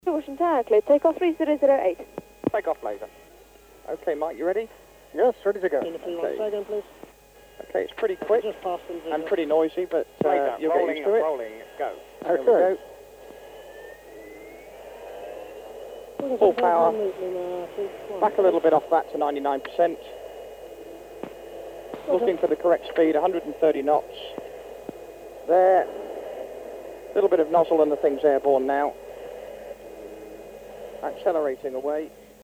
Harrier take off RAF Wittering 1993
harrier-takeoff-clip.mp3